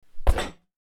bouncy hit effect 01
bonk bouncy effect fist hit impact short thud sound effect free sound royalty free Sound Effects